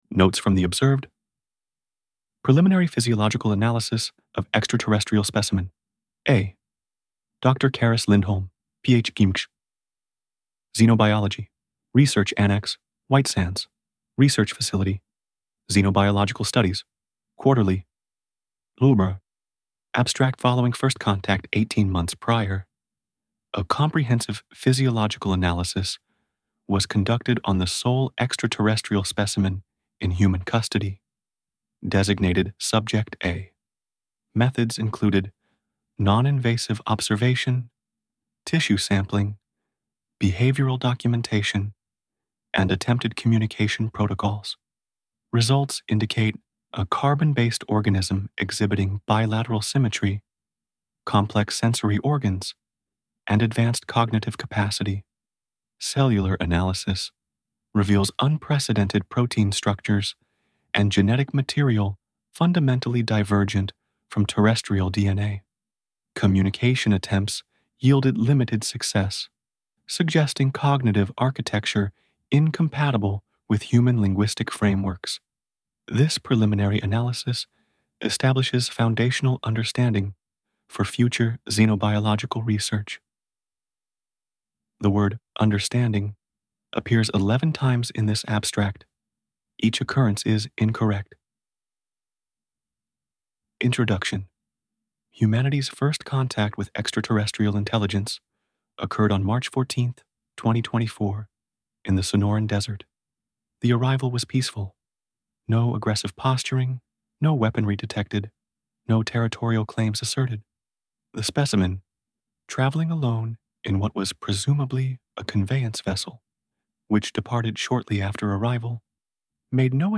Narrated by AI (Thomas voice) via ElevenLabs
full_audiobook.mp3